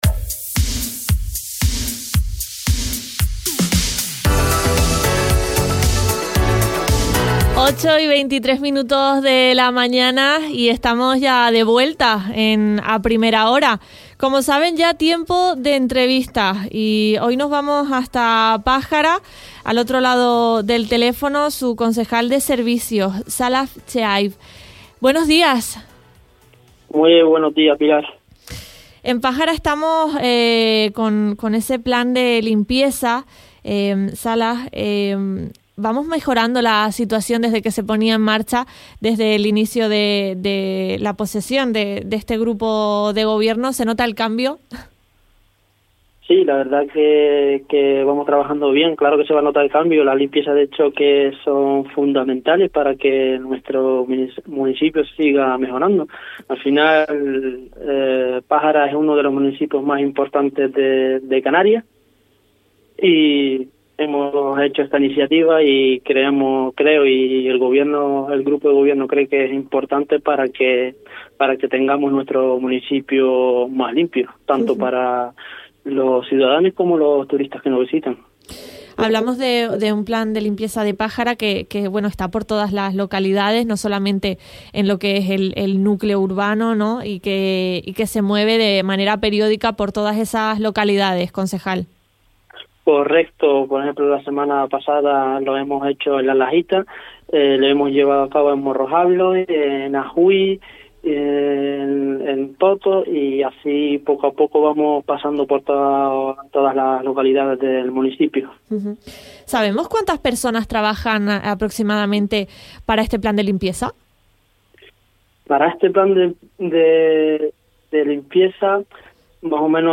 Entrevistas
Esta mañana contactamos con el concejal de Servicios del Ayuntamiento de Pájara, Salah Chehaib, quien ha expresado que esta acción nacía desde la necesidad de garantizar el mantenimiento en las diferentes localidades de Pájara.